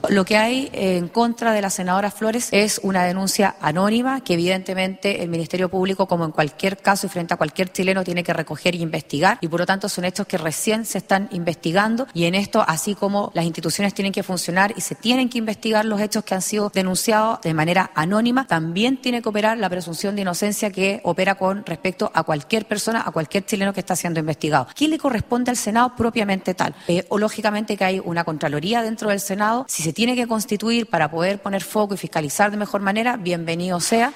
A las declaraciones se sumó la presidenta del Senado, Paulina Núñez, quien sostuvo que, por ahora, existe únicamente una denuncia anónima que debe ser investigada por el Ministerio Público.
CUNA-PRESIDENTA-SENADO.mp3